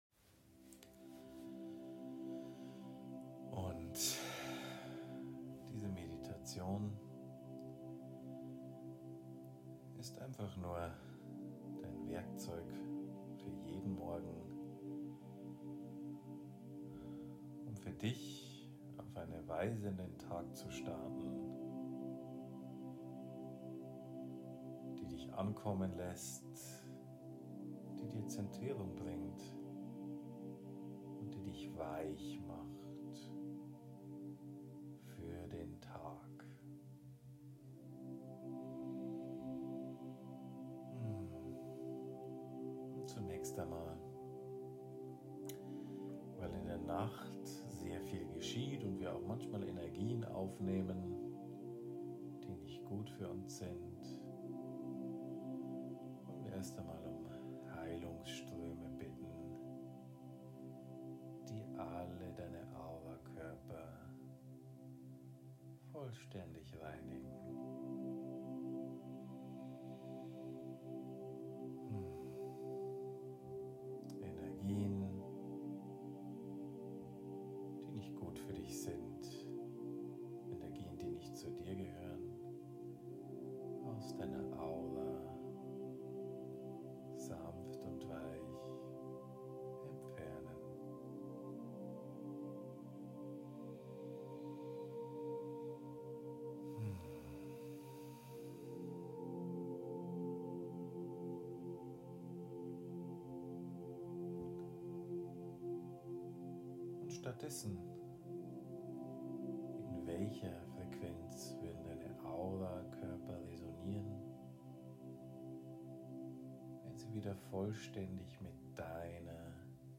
In dieser liebevoll, weichen Meditationsheilung widmen wir uns einer energetischen Entstressung des Körper-Systems, die der wir die obigen Aspekte adressieren.
Morgenmeditation.mp3